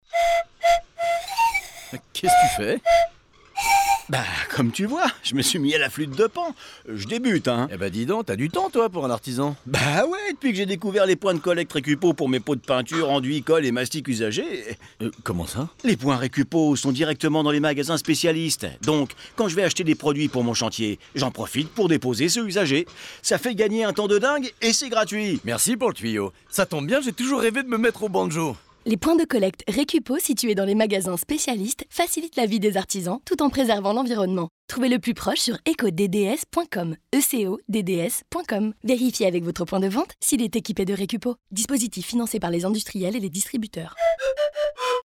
De mai à juin puis de septembre à octobre, notre spot radio met en scène deux artisans à la découverte de nouvelles passions !